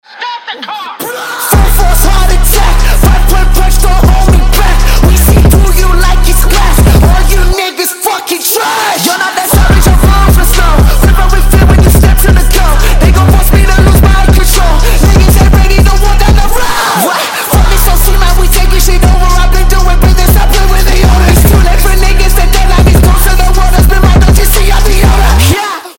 • Качество: 320, Stereo
жесткие
мощные басы
Trap
Alternative Hip-hop
злые
мрачные
Alternative Rap
агрессивные
Мощный и напористый альтернативный рэп и трэп.